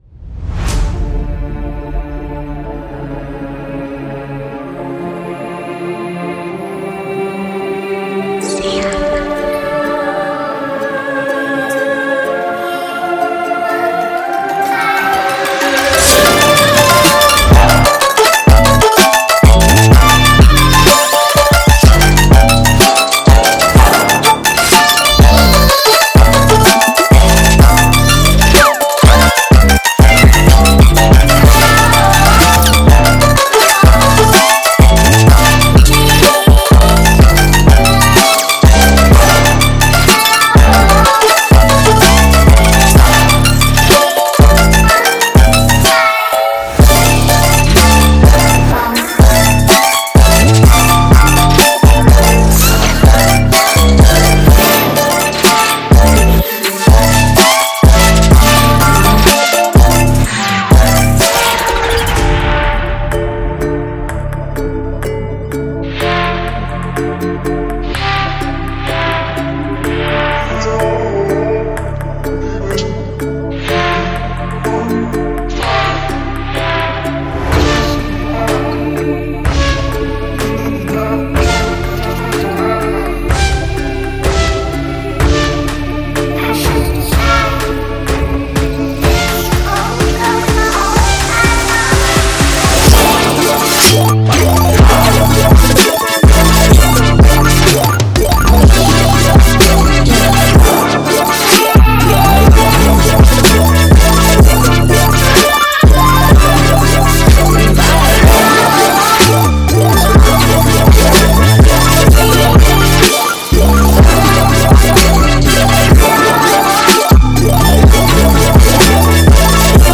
– 19 basses (10 808s, 6 bass plucks, 3 reeses)
– 61 oneshots (melodic plucks, flutes, guitars, etc…)
– 44 percs / layers (includes 15 loops)
– 73 FX (risers, sfx, transitions, vox)